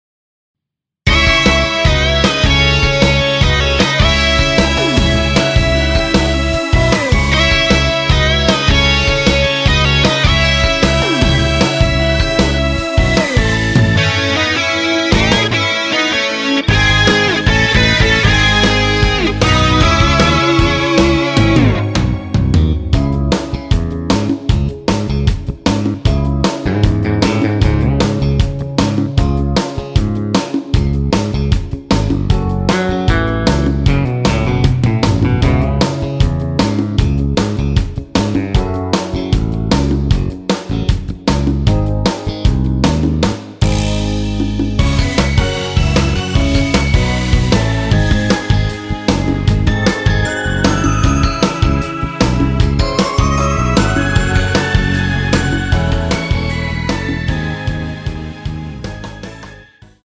원키 MR
C#m
앞부분30초, 뒷부분30초씩 편집해서 올려 드리고 있습니다.
중간에 음이 끈어지고 다시 나오는 이유는